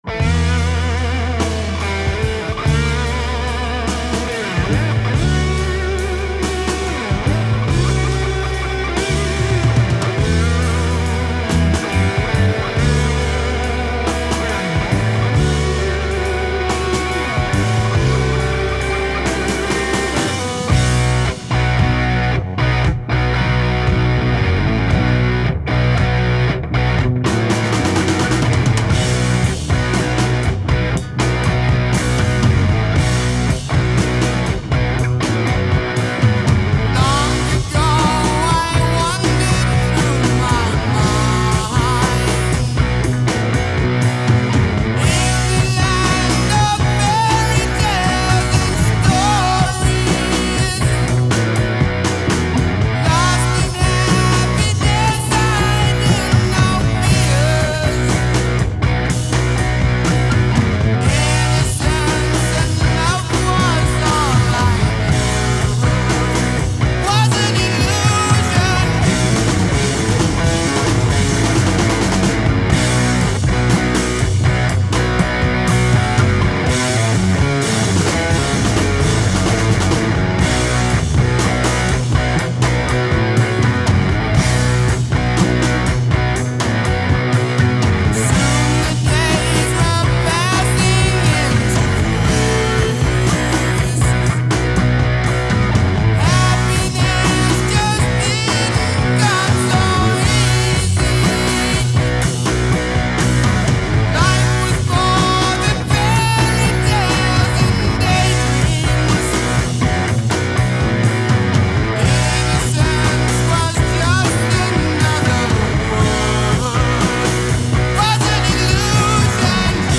Heavy Metal, Hard Rock, Proto-Metal